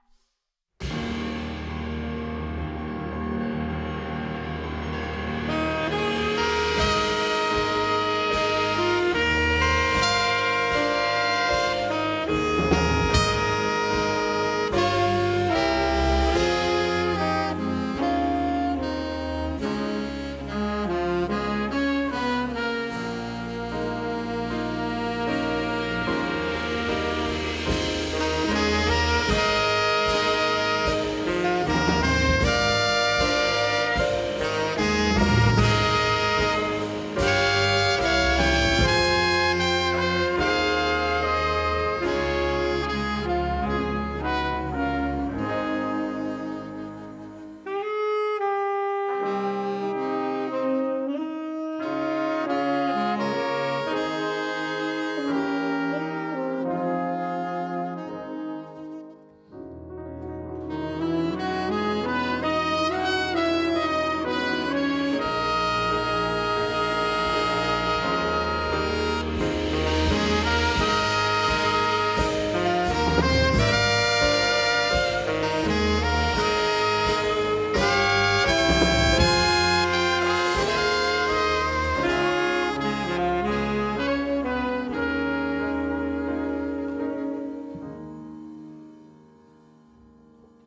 is a tone poem for jazz sextet